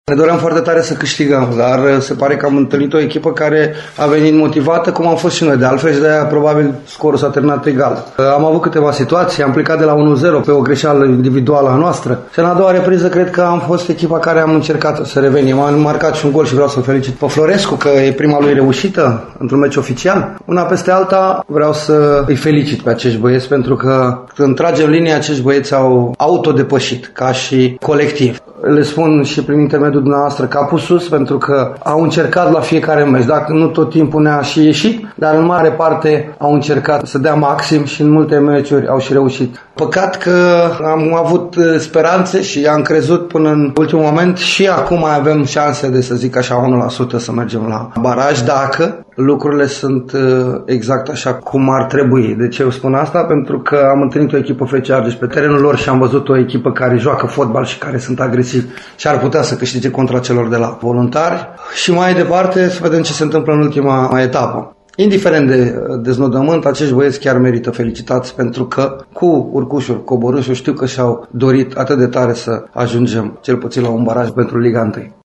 Antrenorul Reșiței, Flavius Stoican, a declarat la final că echipei sale îi rămân 1% șanse de accedere la baraj: